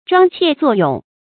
装怯作勇 zhuāng qiè zuò yǒng
装怯作勇发音